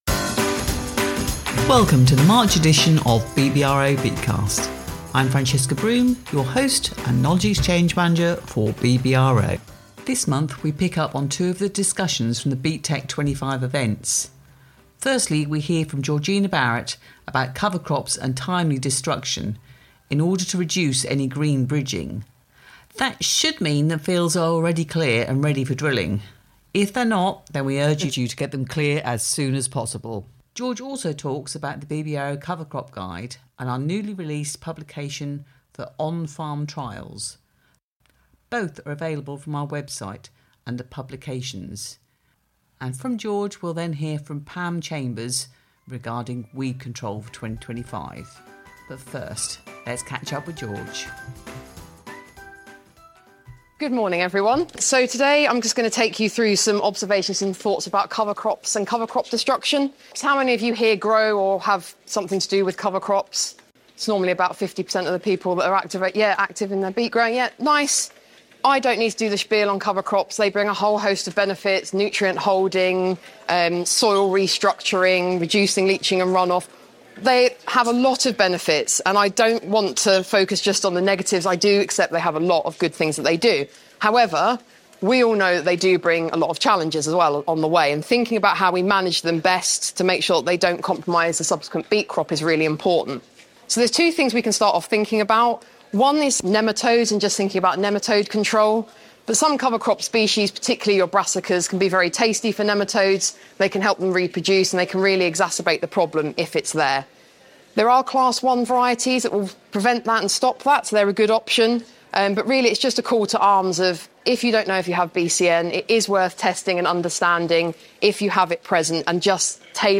1 BeetCast March; Covering off cover crops and planning early weed control 26:07 Play Pause 3d ago 26:07 Play Pause Lire Plus Tard Lire Plus Tard Des listes J'aime Aimé 26:07 Disease pressures are probably not on growers radars at present, but in order to break the green bridge we need to ensure fields are clean prior to drilling, especially where cover crops have been in use. We listen in to a presentation